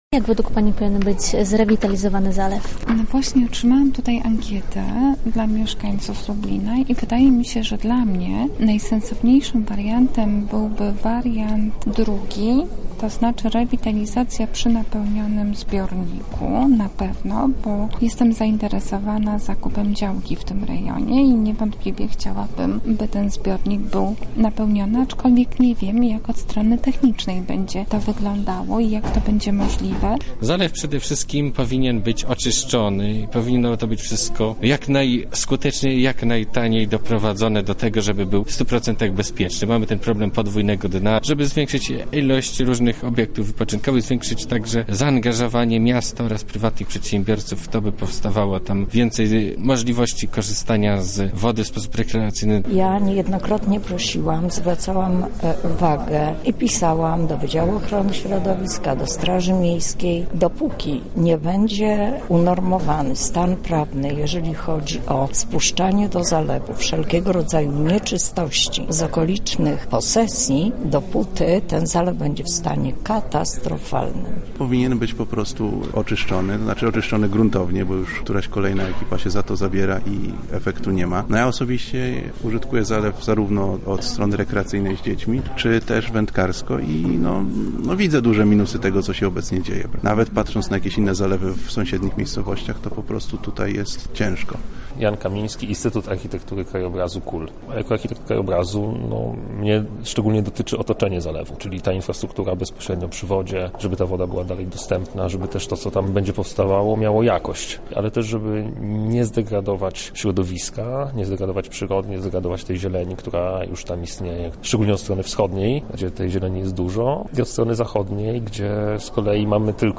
Na miejscu była nasza reporterka, która spytała się obecnych na zebraniu lublinian o ich zdanie na ten temat:
sonda